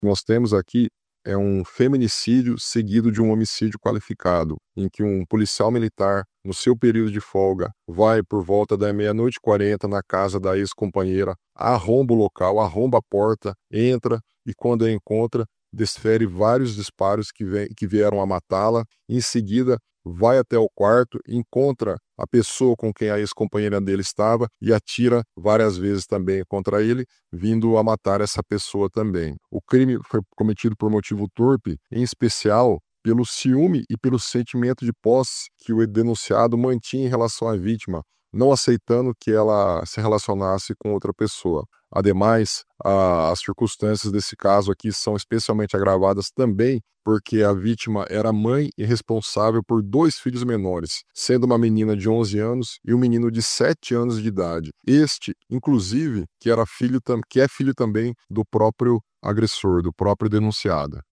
Ouça o que diz o promotor de Justiça Vinicius Bento Galli.